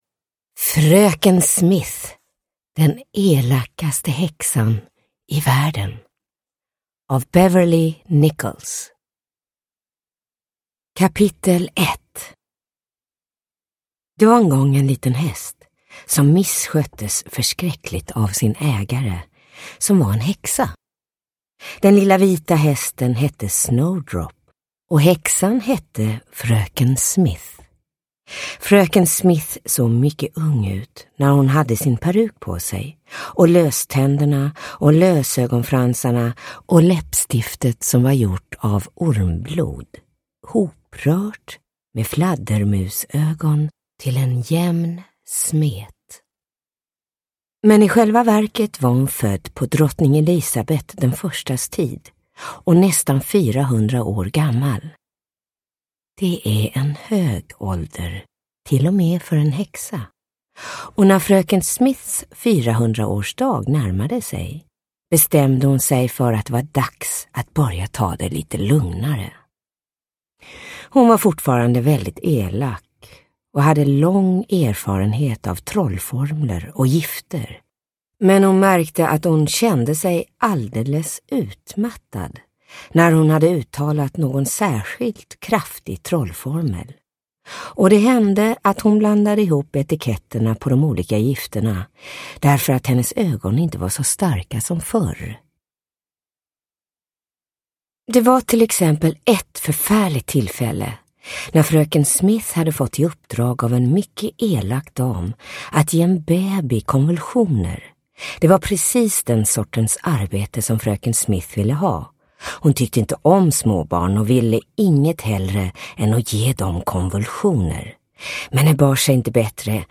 Fröken Smith, den elakaste häxan i världen (ljudbok) av Beverley Nichols